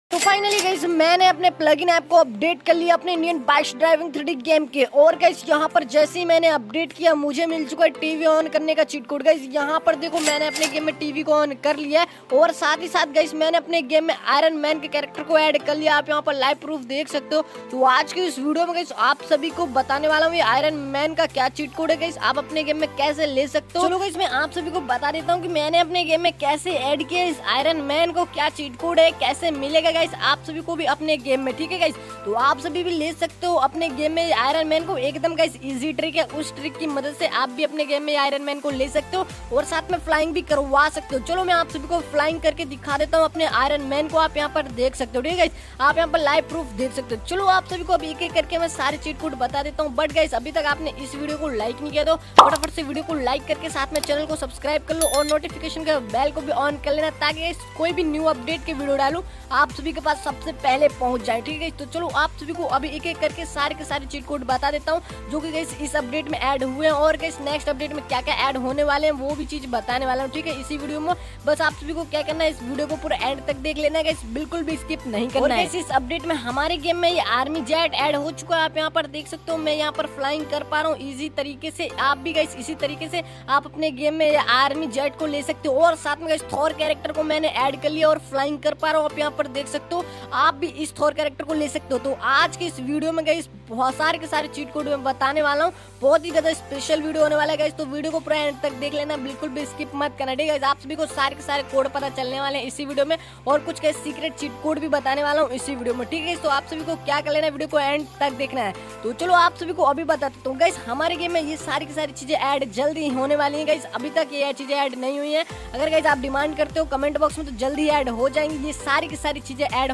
segrat chee indian bike driving sound effects free download